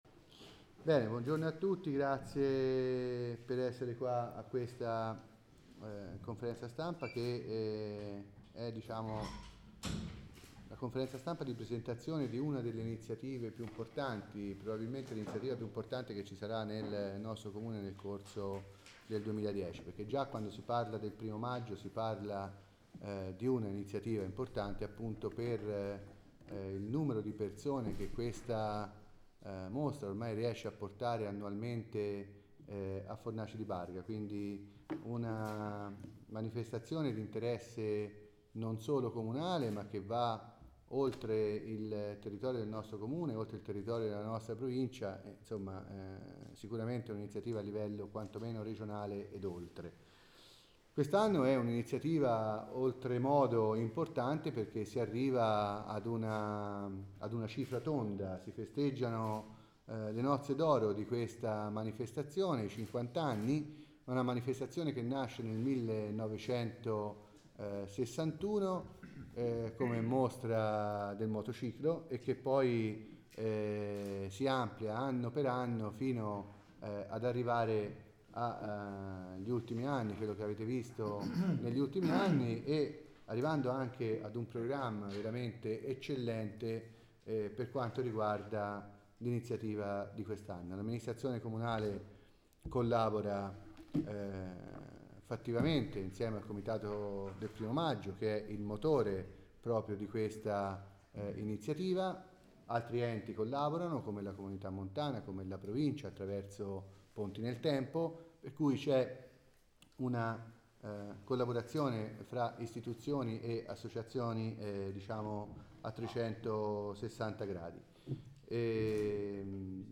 Presentato nella sede comunale il programma della cinquantesima edizione di 1° Maggio a Fornaci.
1may_palazzo_pancrazi_april2010.mp3